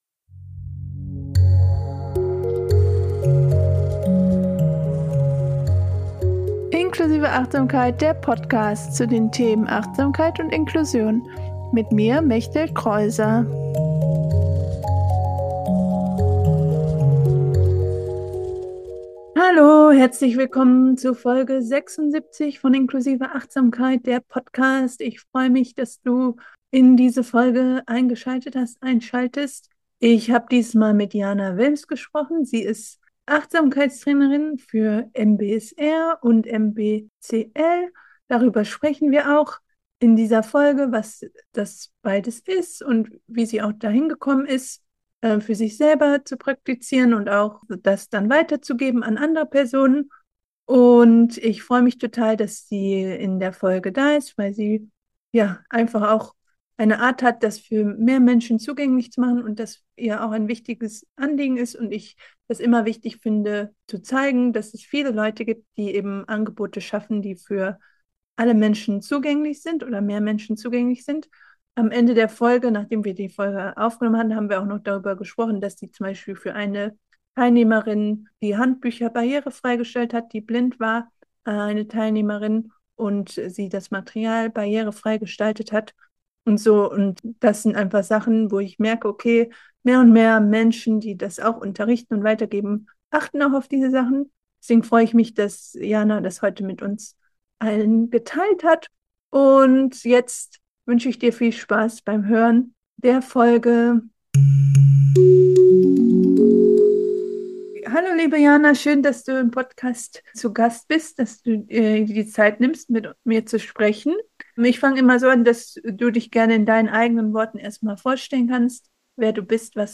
76 - Zugängliche Achtsamkeit - Interview